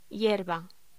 Locución: Hierba